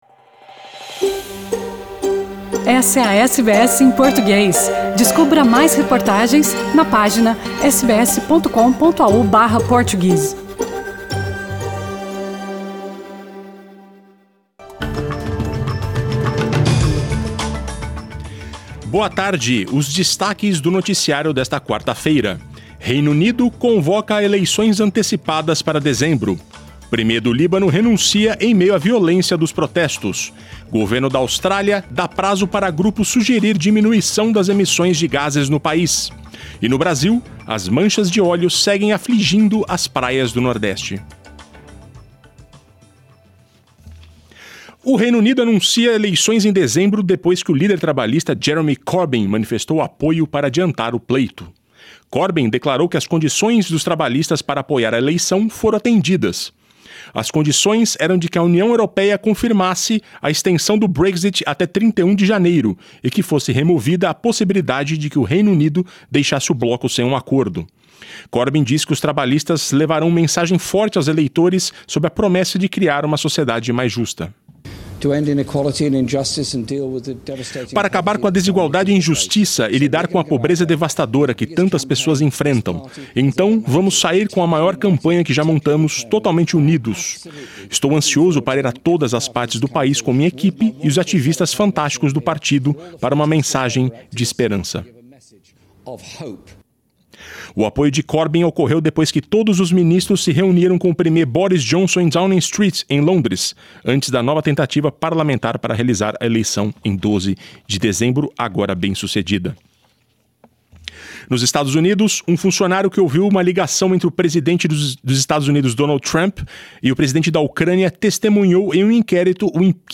The news bulletin for Wednesday, October 29th